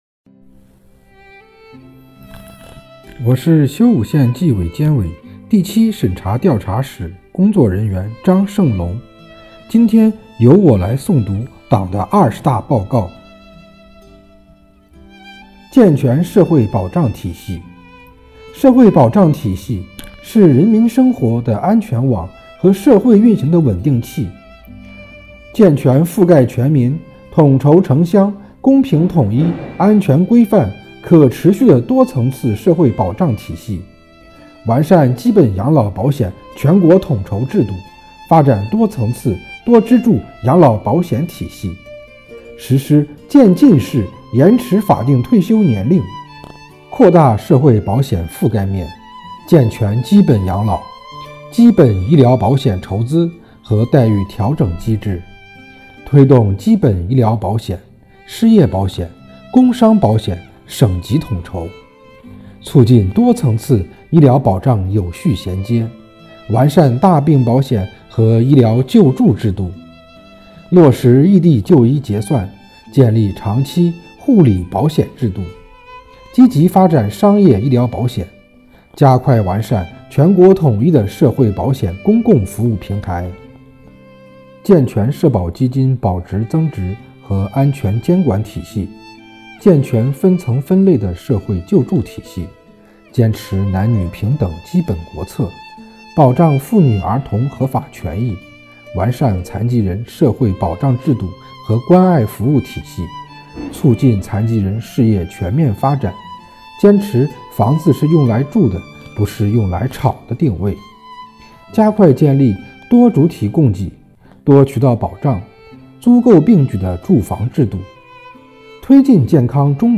为深入学习宣传贯彻党的二十大精神，切实把全市纪检监察干部的思想统一到党的二十大精神上来，把力量凝聚到党的二十大确定的各项任务上来，即日起，“清风焦作”公众号推出“共学二十大•接力读原文”活动，摘选各单位宣讲员诵读党的二十大报告原文，敬请关注！
诵读内容